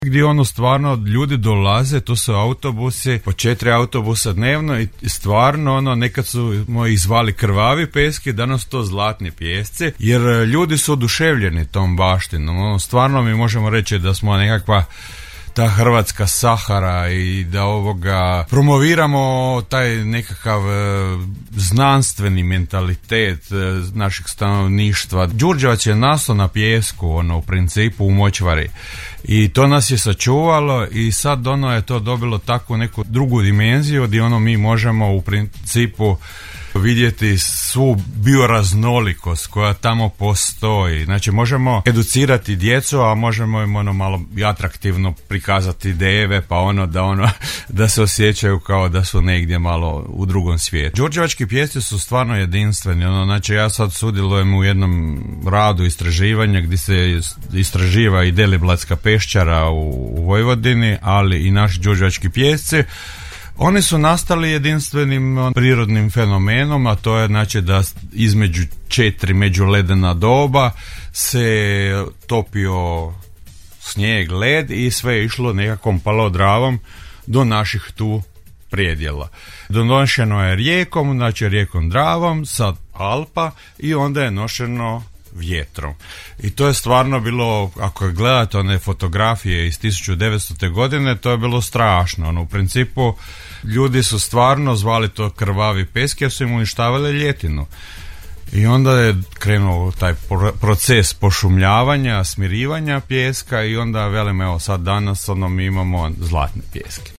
je u emisiji Gradske teme u programu Podravskog radija, govorio o prirodnoj i kulturnoj baštini Đurđevca, posebno izdvojivši Đurđevačke pijeske;